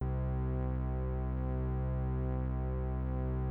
Synth Bass (Everything We Need).wav